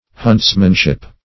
Search Result for " huntsmanship" : The Collaborative International Dictionary of English v.0.48: Huntsmanship \Hunts"man*ship\, n. The art or practice of hunting, or the qualification of a hunter.